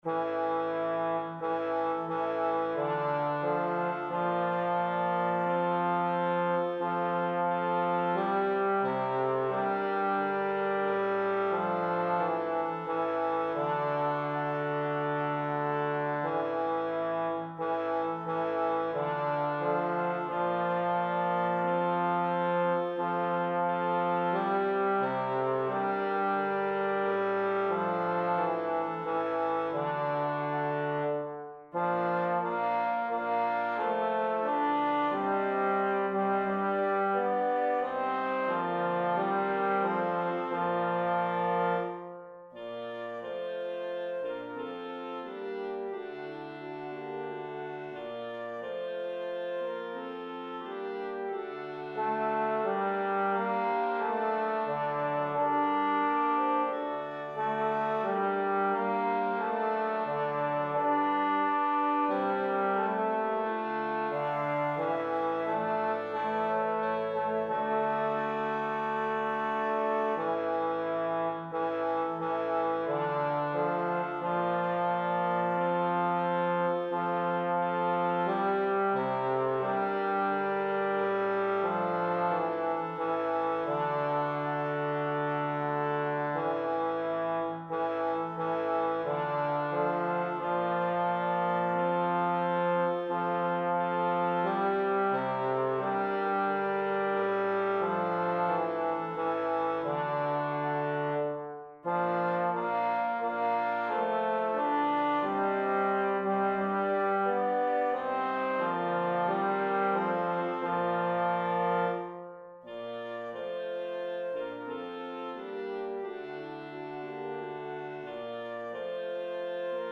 Bass
Anthem